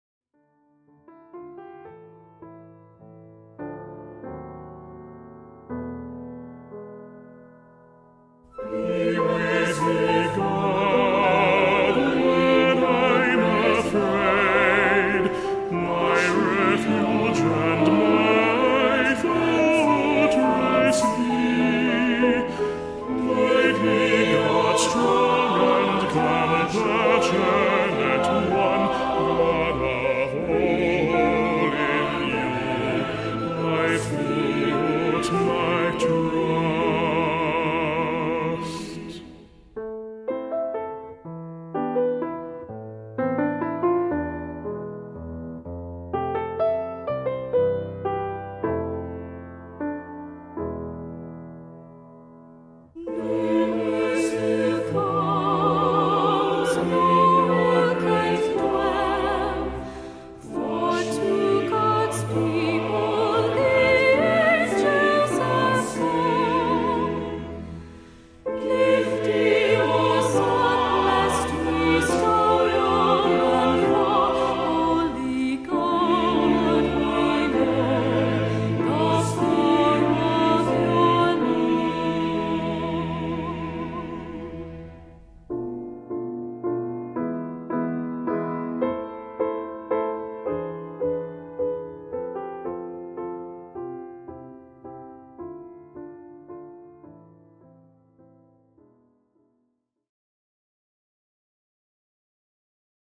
Voicing: Cantor,SATB